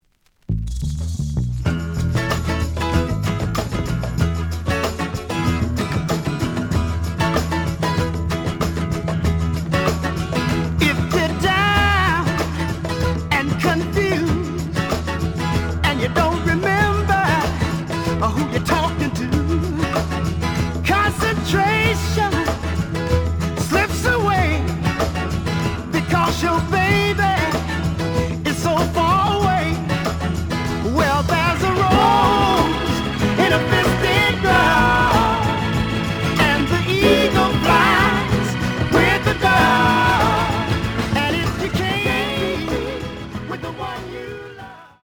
The audio sample is recorded from the actual item.
●Genre: Soul, 70's Soul
Slight sound cracking on both sides.)